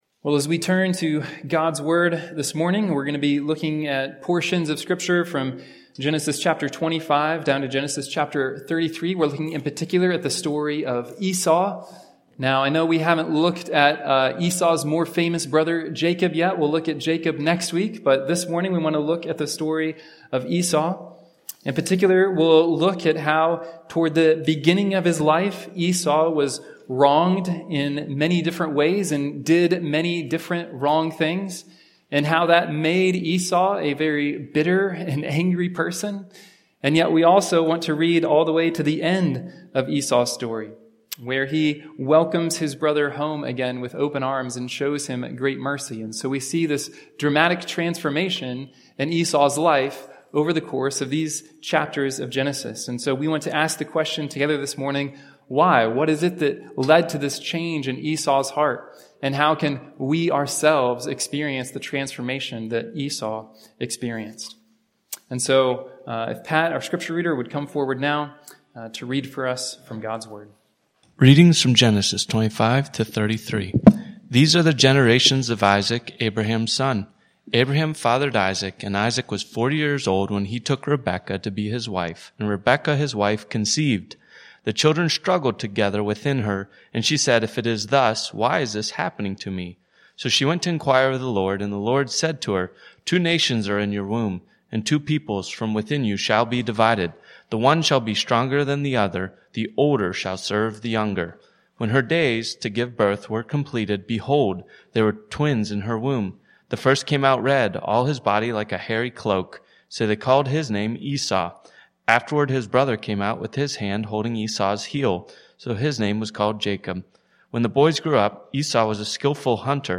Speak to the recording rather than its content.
Weekly preaching from Providence Church (Pittsburgh, PA)